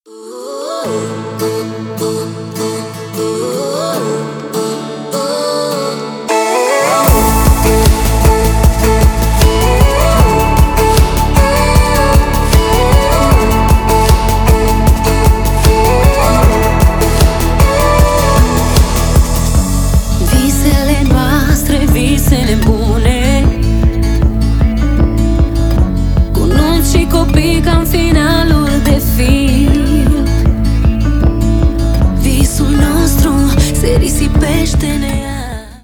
Stereo
Поп